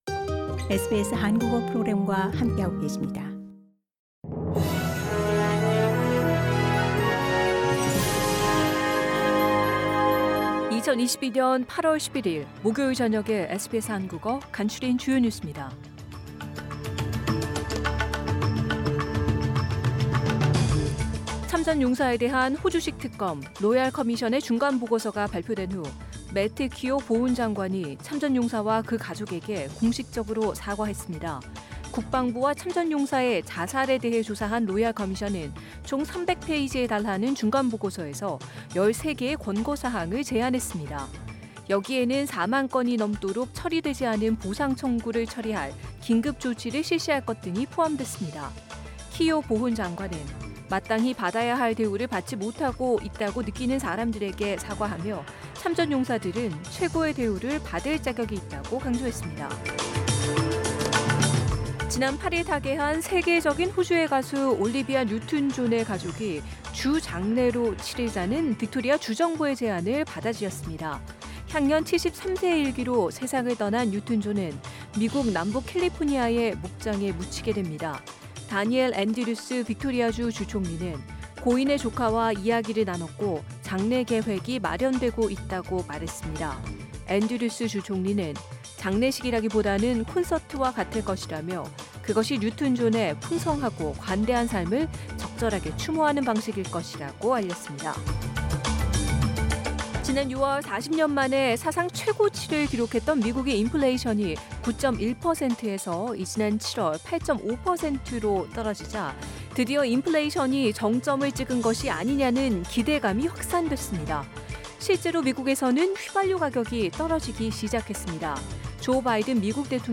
2022년 8월 11일 목요일 저녁 SBS 한국어 간추린 주요 뉴스입니다.